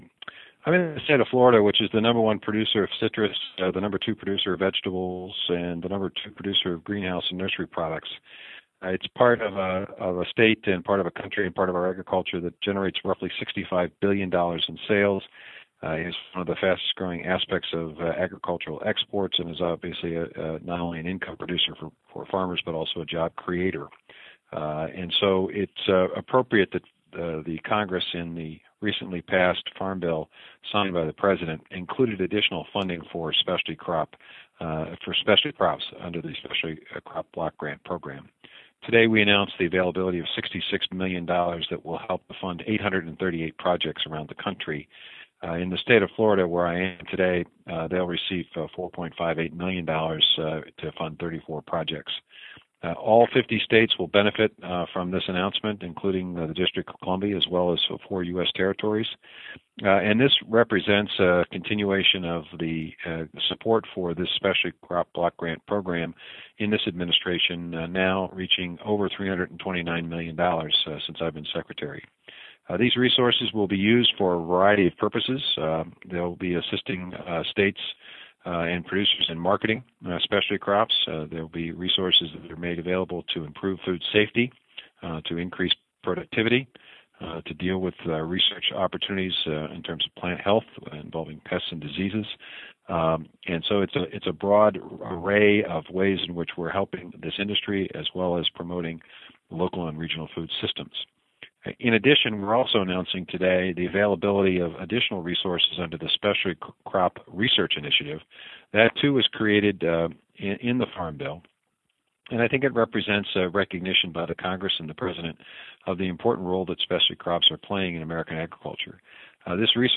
Press Conference Call with Ag Secretary Tom Vilsack, USDA